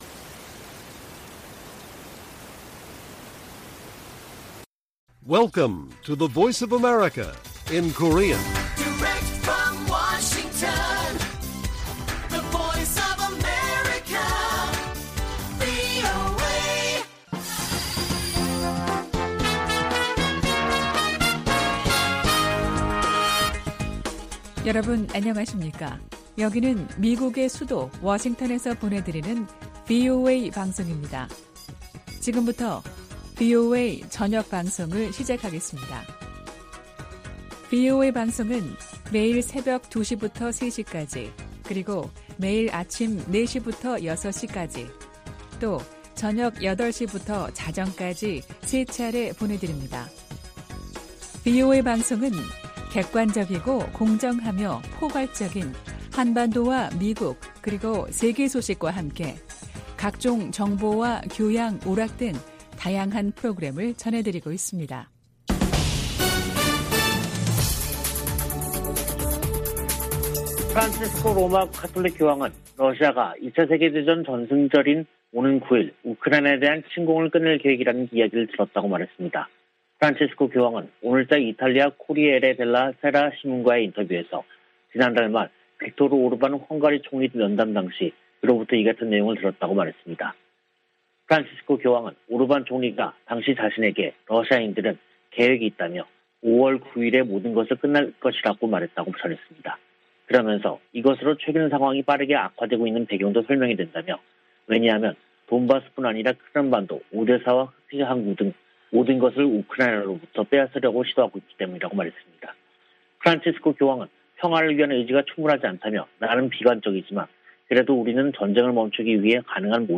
VOA 한국어 간판 뉴스 프로그램 '뉴스 투데이', 2022년 5월 3일 1부 방송입니다. 미 국무부는 북한 풍계리 핵실험장 복구 조짐에 대해 위험한 무기 프로그램에 대처할 것이라고 밝혔습니다. 한국의 대통령직 인수위원회는 북한 비핵화 추진을 국정과제로 명시했습니다. 한국과 중국의 북 핵 수석대표들은 북한의 핵실험 재개 움직임 등에 대해 상황 악화를 막기 위해 공동으로 노력하기로 다짐했습니다.